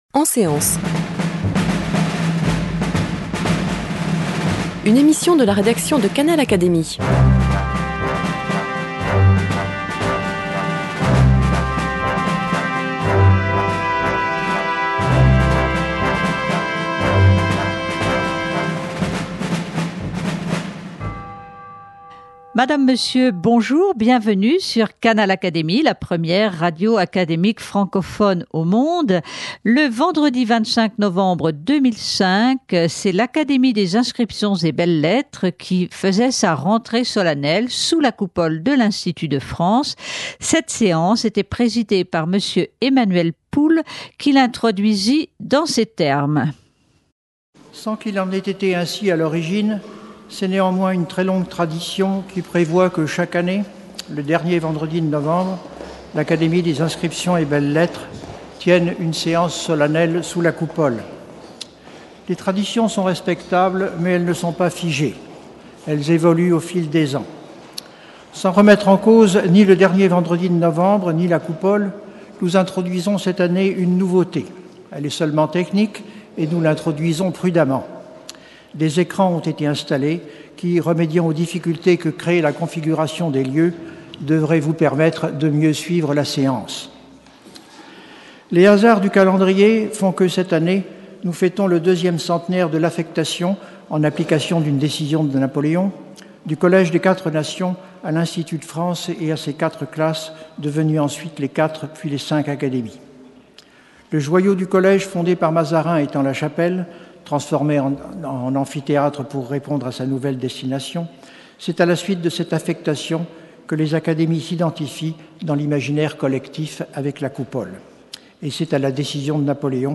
Retransmission de la séance solennelle de rentrée de l’Académie des inscriptions et belles-lettres, le vendredi 25 novembre 2005